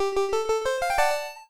LevelUp3.wav